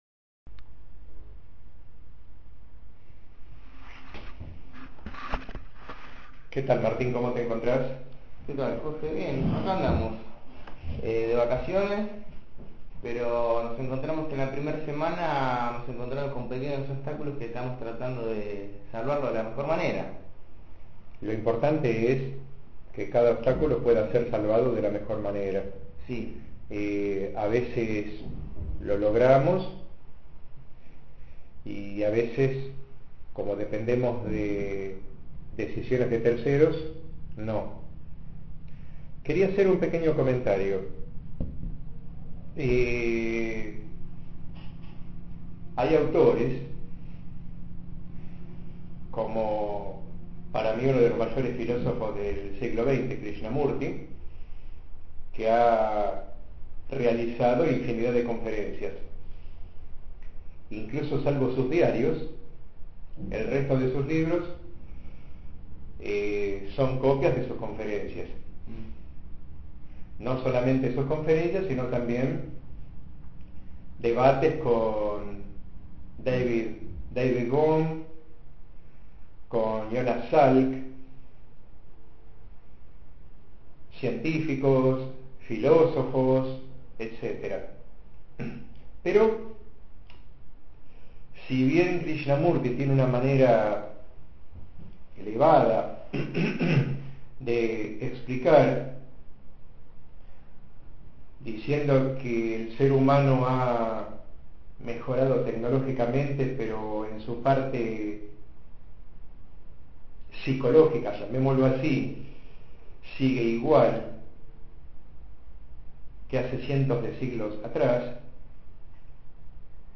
Charla sobre mensajes